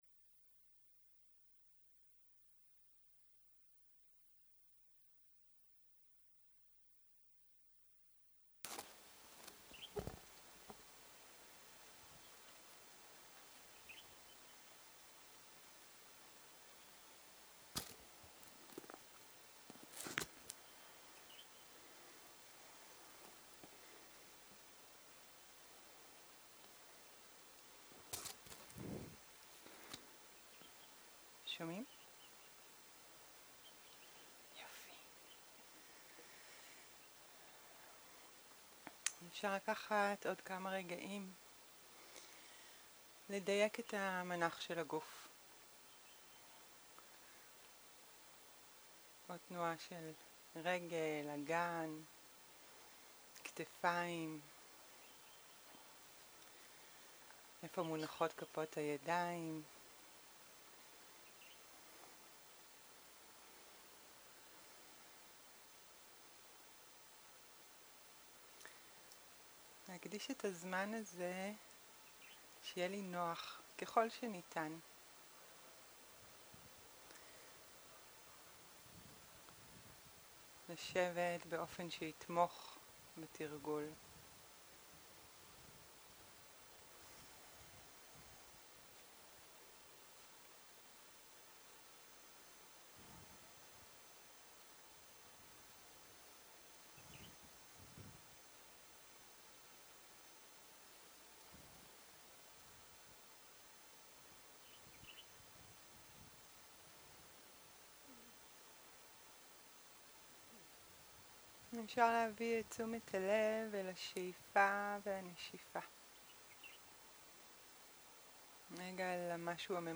מדיטציה מונחית צהריים